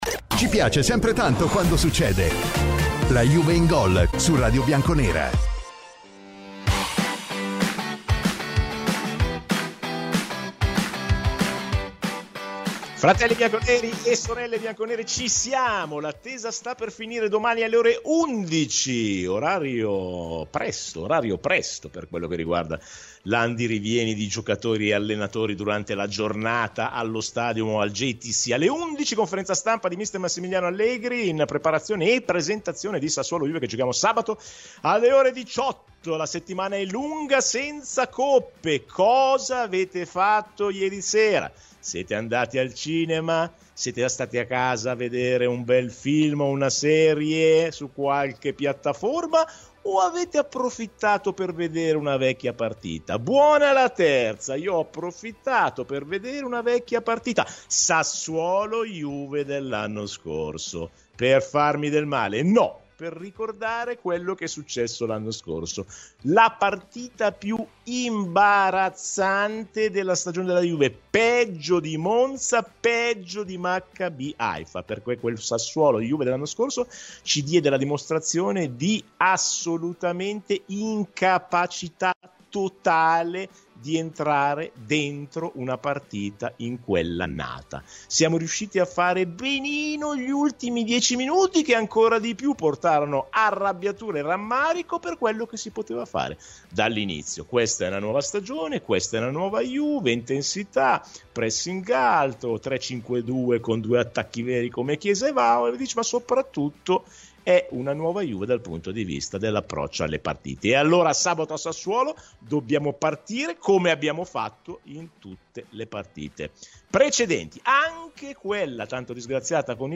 microfoni di Radio Bianconera